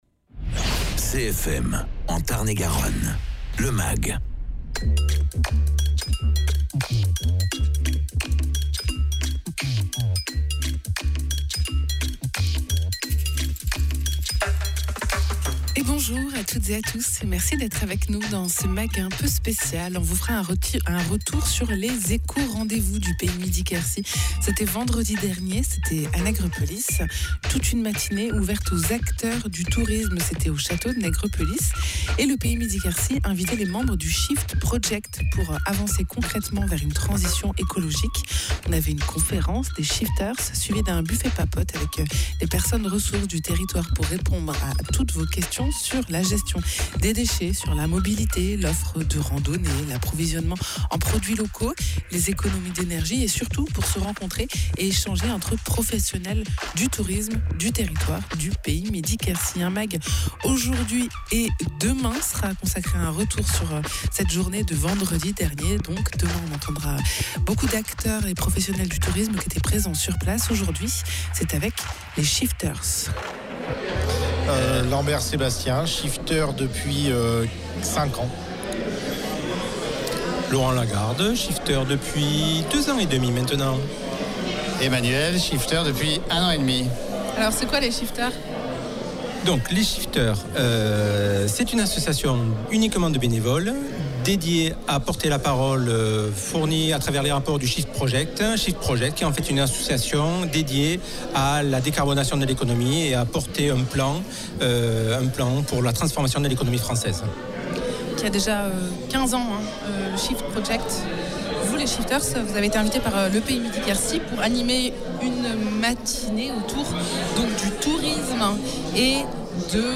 The Shifters est une association d’intérêt général qui rassemble des bénévoles engagés dans la transition bas-carbone de la France et de l’Europe. Vendredi 20 février, ils étaient invités par le Pays Midi Quercy pour le 1er ECO RDV des Pros du tourisme, pour avancer concrètement vers une transition écologique.